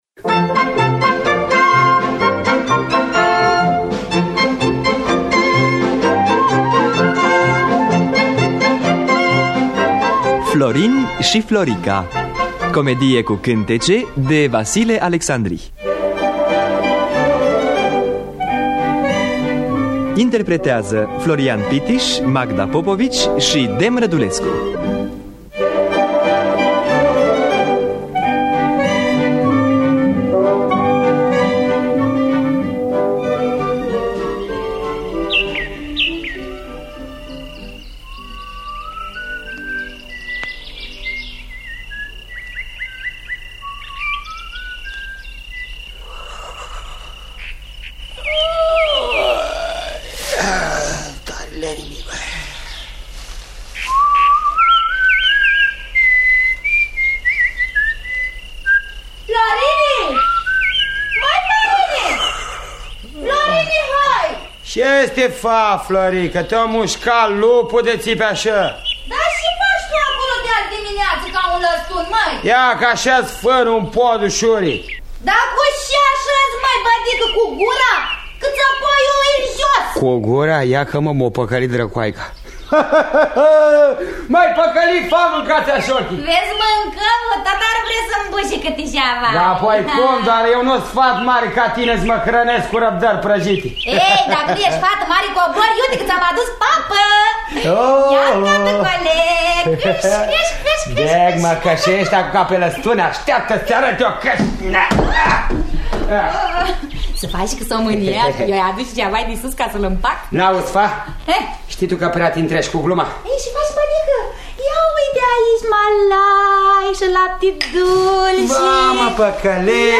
În distribuţie: Florian Pittiş, Magda Popovici, Dem Rădulescu.